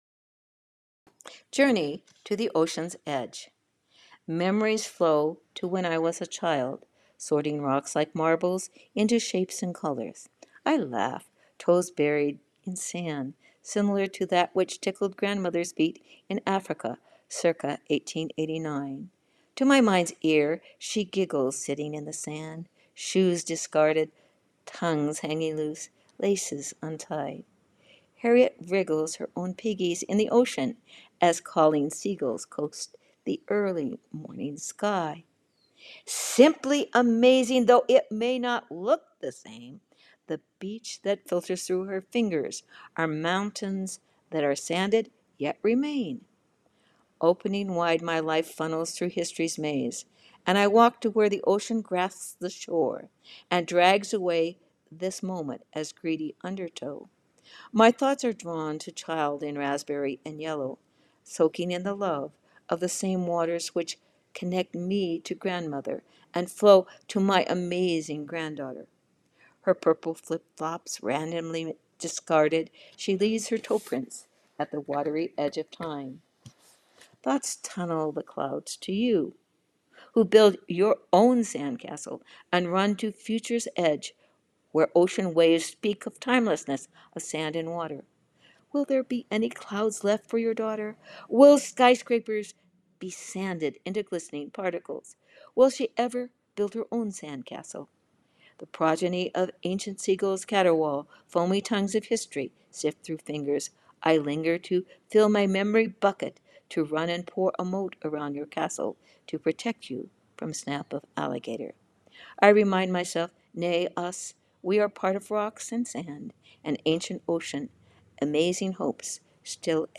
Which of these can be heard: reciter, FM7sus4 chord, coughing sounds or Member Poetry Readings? Member Poetry Readings